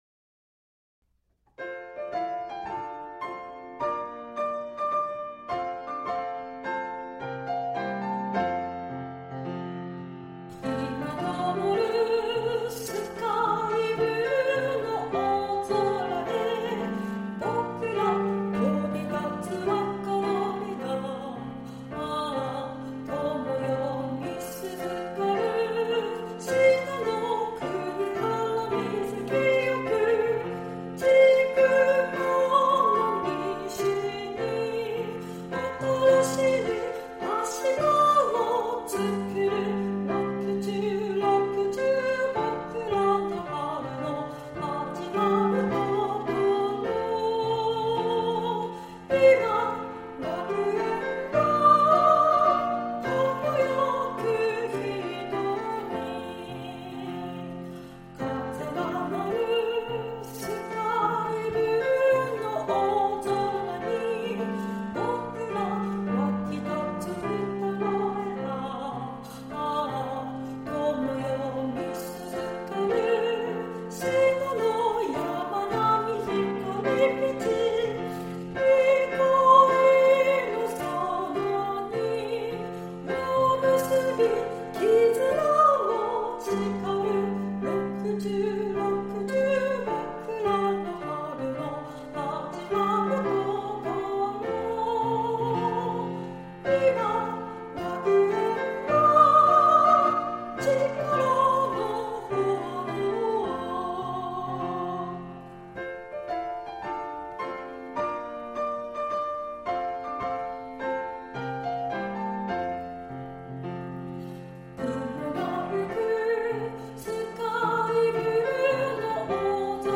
校歌　メロディ.mp3